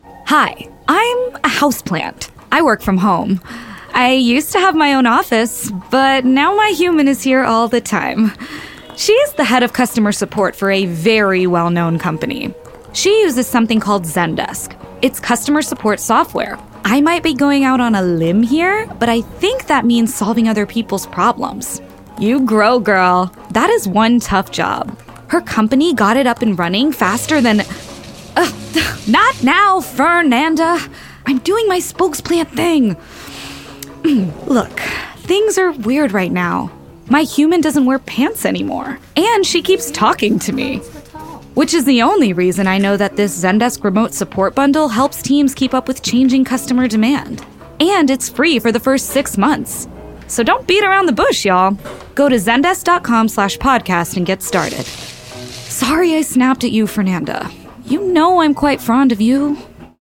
When lockdown and working from home became the norm, Zendesk wanted to tout how easy it was to implement and use—even if if you ARE living through a global pandemic. We decided with our media company that podcast ads were the way to go.
So…with the help of Best Buy delivery and a new mic, I became one.